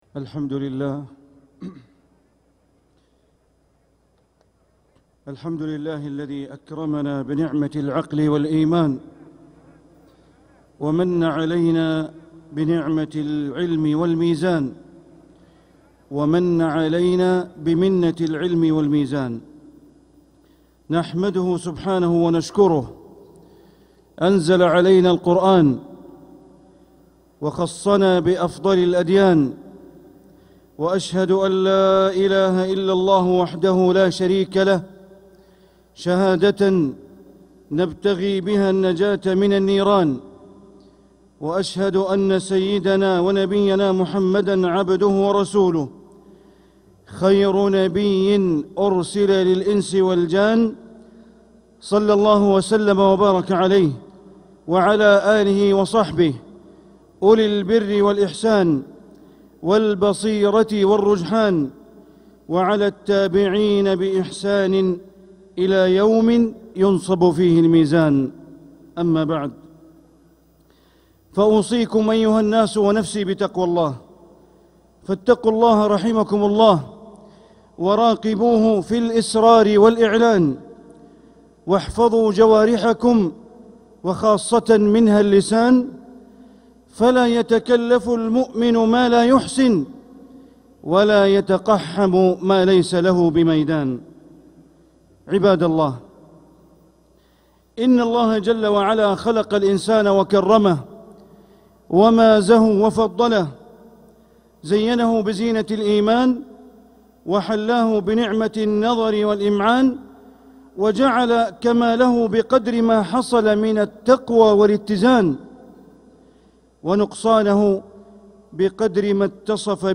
خطبة الجمعة 18 ربيع الآخر 1447هـ > خطب الشيخ بندر بليلة من الحرم المكي > المزيد - تلاوات بندر بليلة